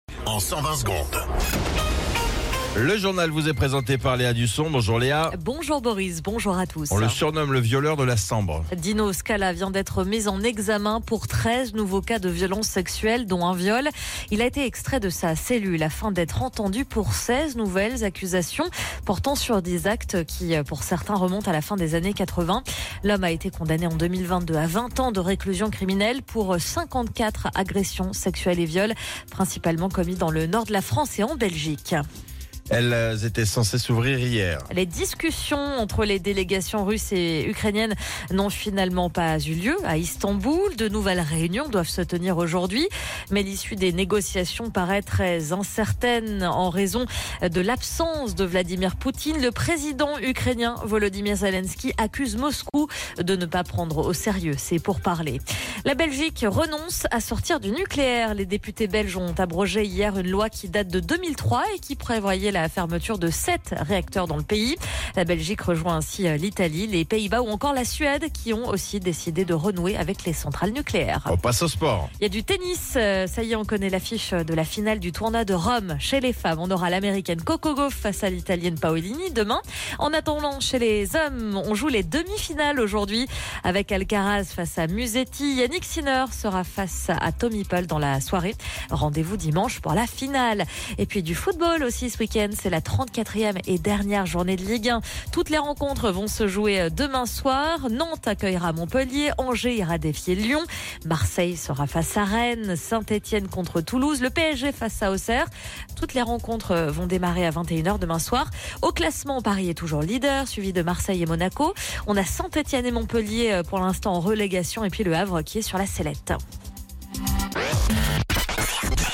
Flash Info National